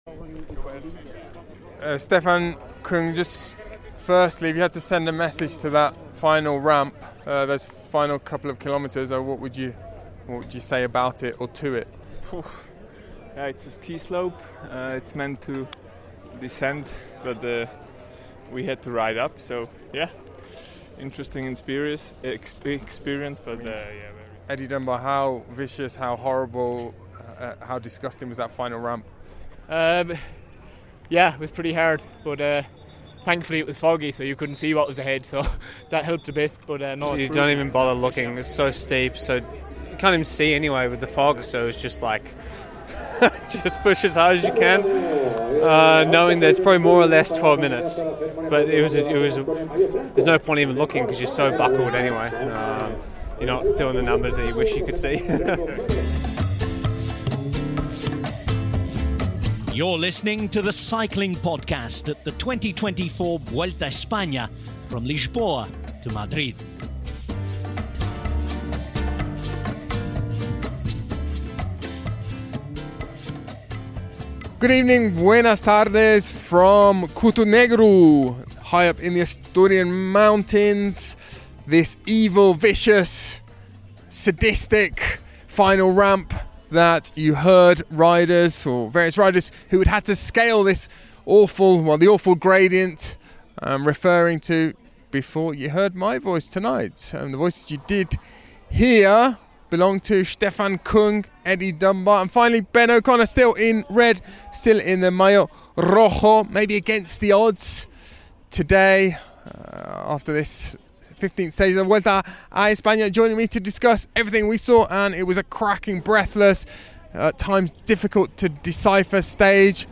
There’ll be analysis, interviews, wistful gazing and tepid takes from on the ground, in the thick of the action - and a return for both popular and unpopular features from previous editions!